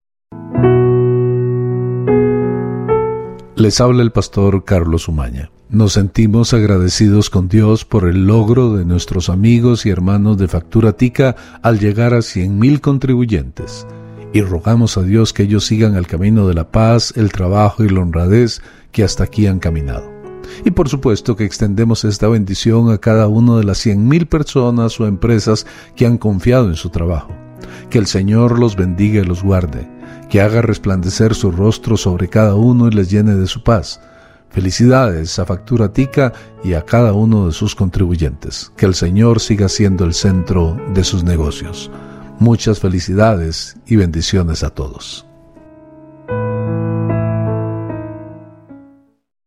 Pastor evangélico